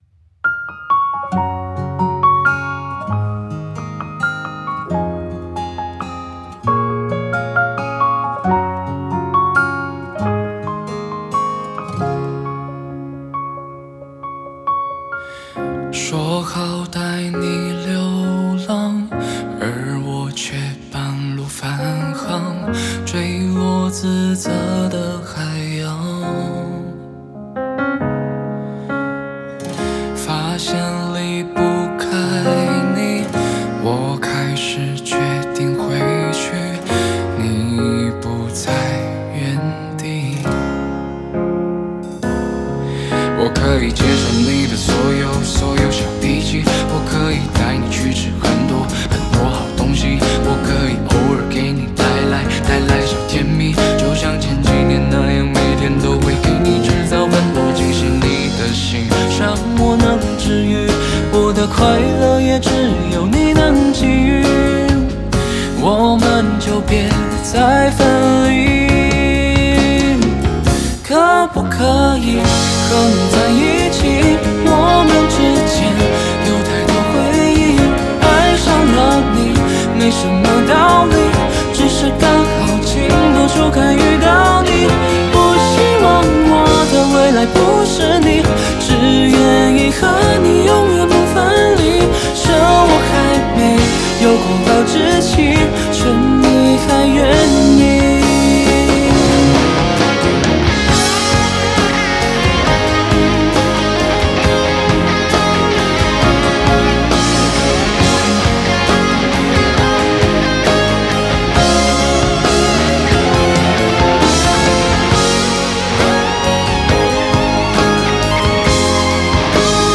Ps：在线试听为压缩音质节选，体验无损音质请下载完整版
全球巡回演唱会Live限定单曲